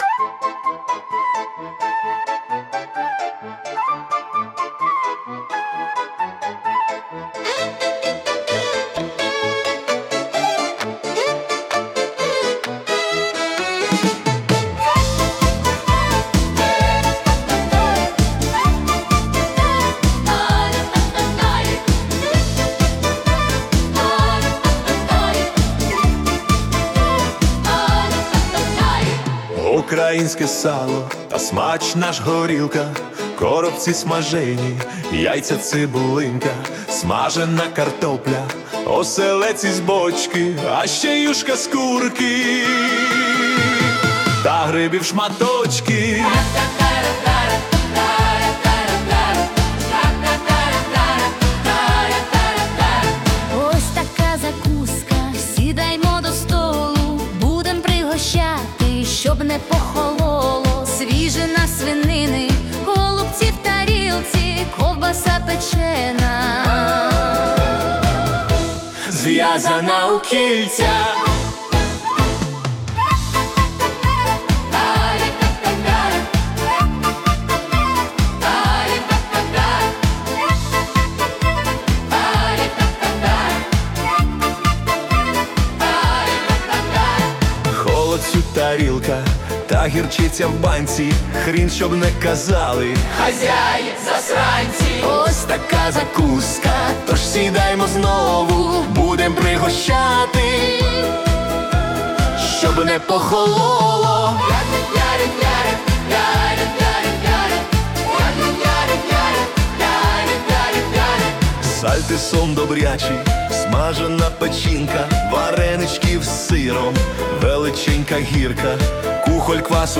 🎵 Жанр: Ukrainian Polka / Wedding Feast